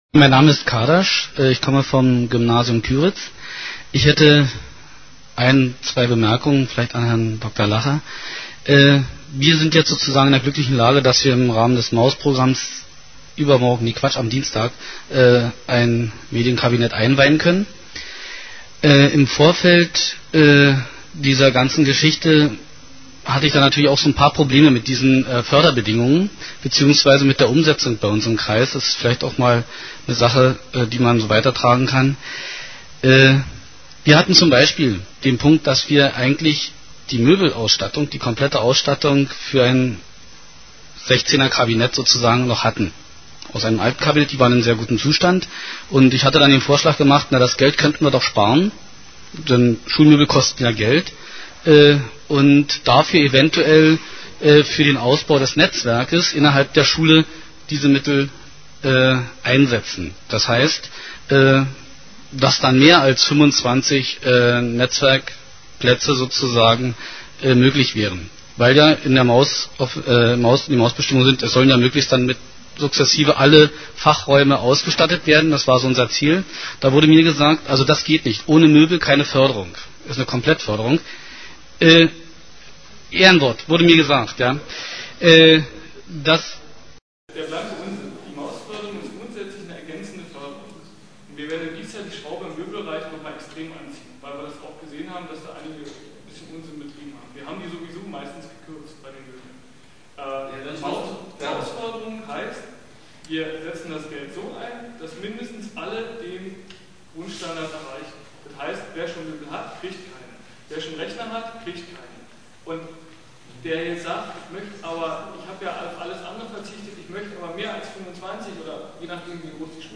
diskussion-frage4.mp3